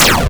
lightning_a.wav